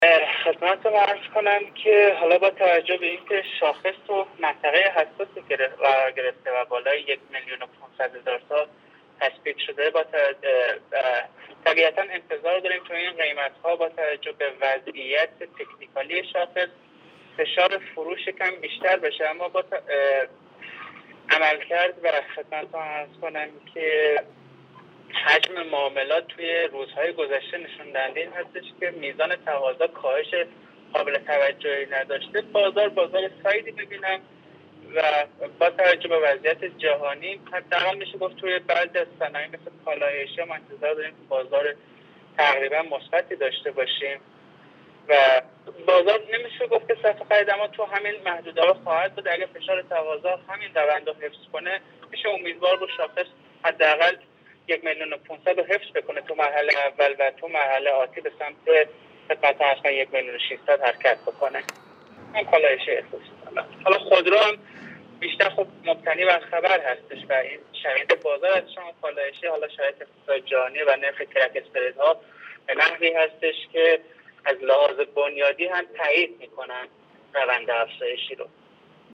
کارشناس بازار سرمایه در گفتگو با بورس نیوز؛